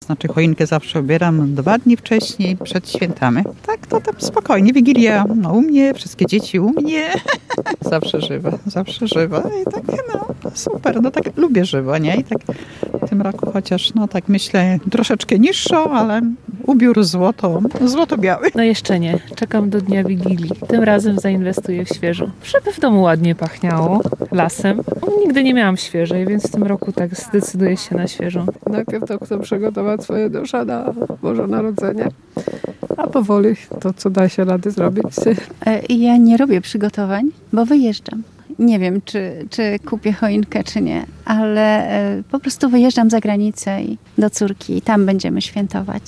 Sprawdzaliśmy czy mieszkańcy Łomży mają w swoich domach świąteczne drzewko.